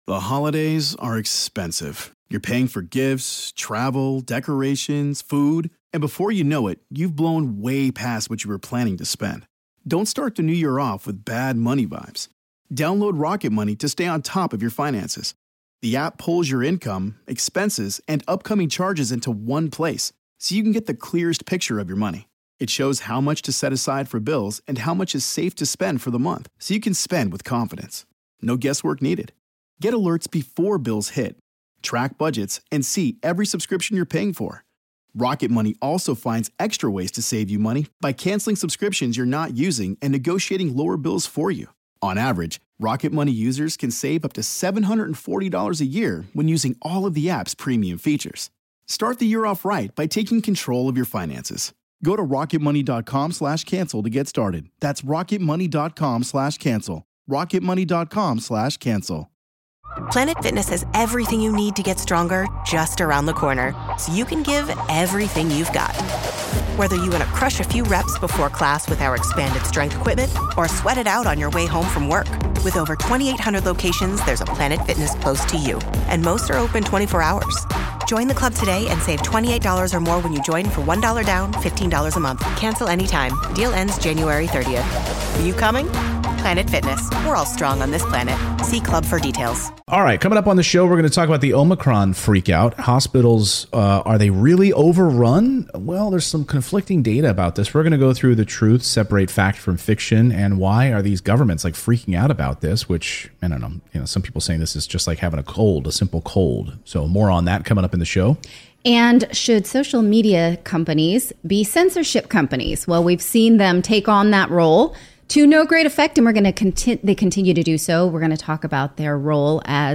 In today's livestream... the Biden administration is warning about a massive outbreak of virus cases in time for Christmas. Governments around the world look at new lockdowns and booster mandates.